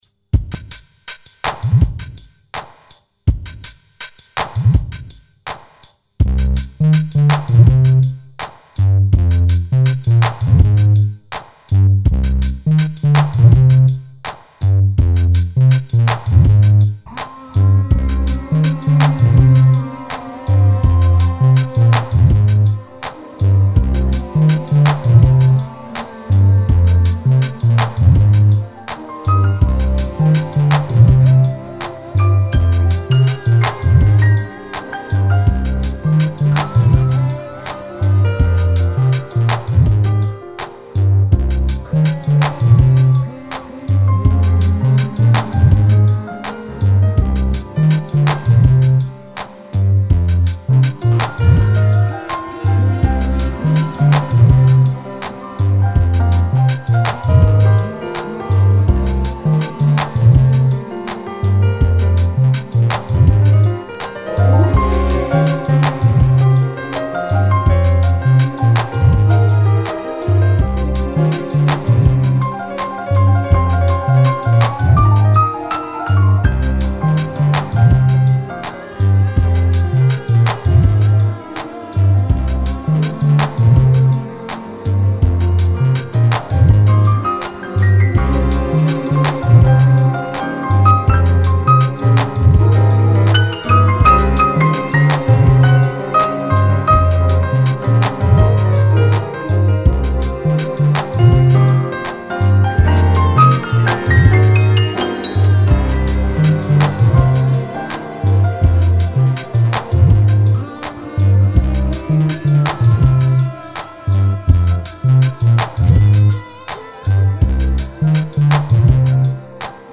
synth test...